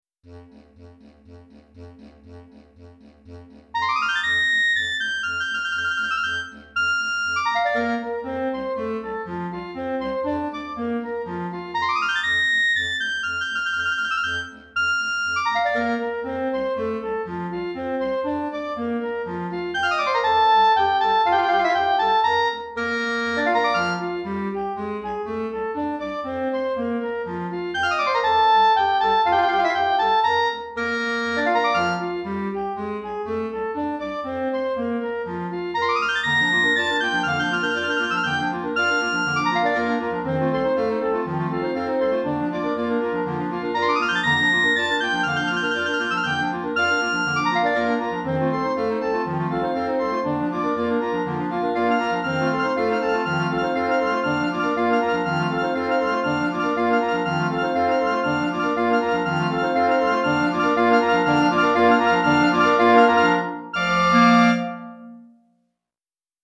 Petite Clarinette Mib 5 Clarinettes en Sib 1 Clari